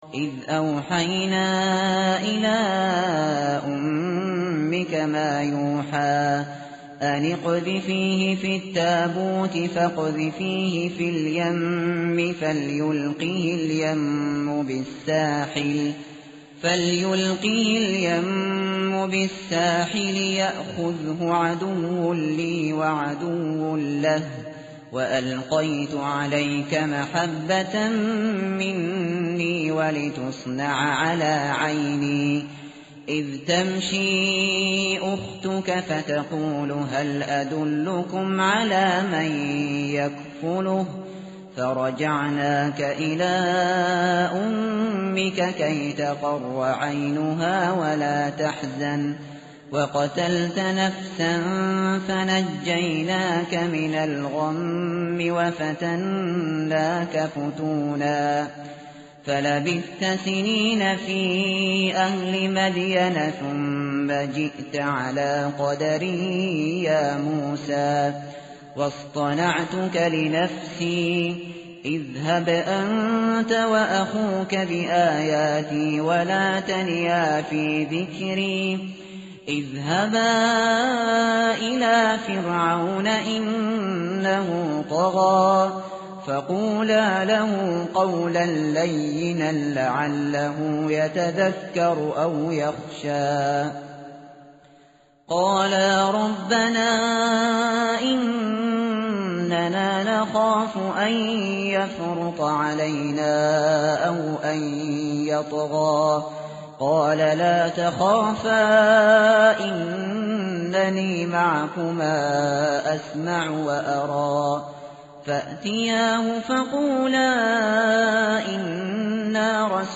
tartil_shateri_page_314.mp3